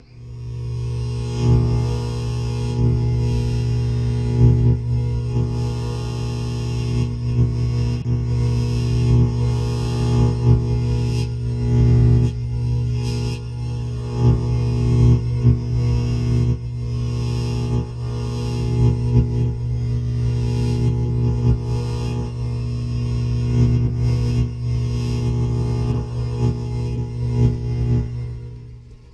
Free Lightsaber sound effects
The moving of the lightsaber was created using the Doppler shift effect when the microphone moved closer and away from the sound source causing the famous pitch shifting when the light saber moves.
I recreated the lightsaber sound using a few hum samples I had, and moving my microphone closer and away from the speaker just like Ben Burtt did.
lightsaber2.wav